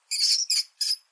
animalworld_rat.ogg